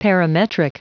Prononciation du mot parametric en anglais (fichier audio)
Prononciation du mot : parametric